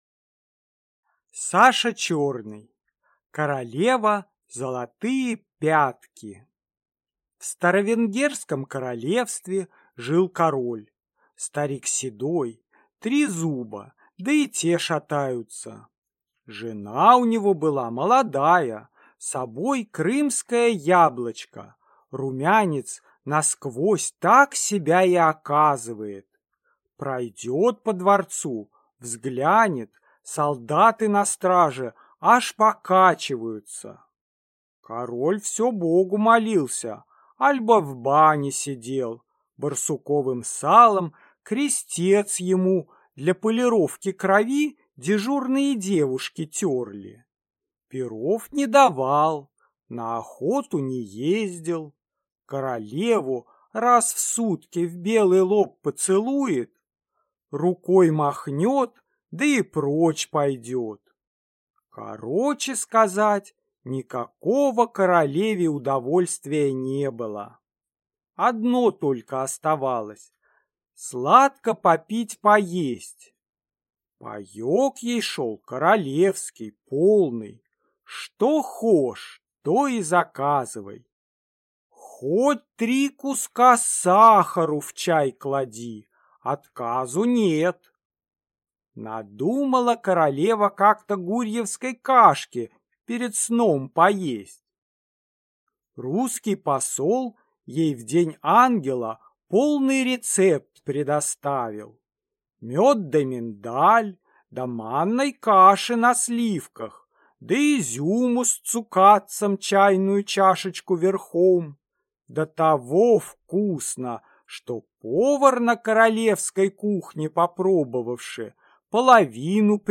Аудиокнига Королева – золотые пятки | Библиотека аудиокниг